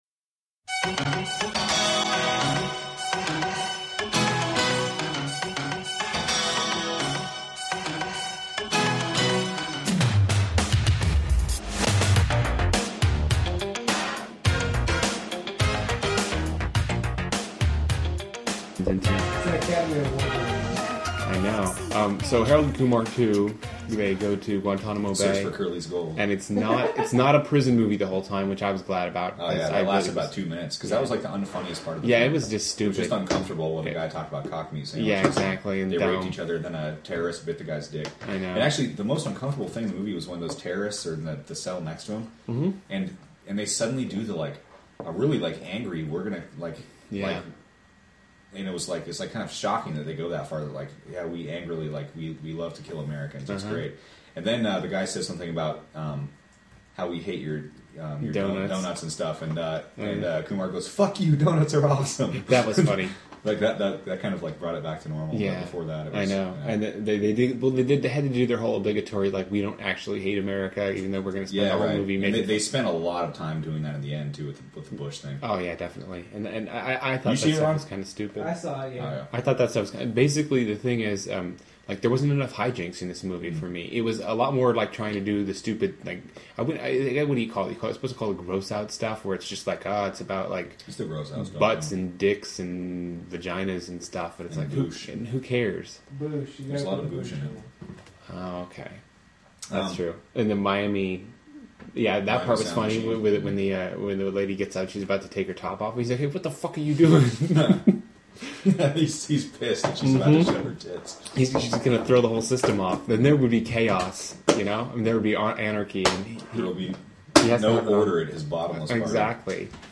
Harold_and_Kumar_2_review.mp3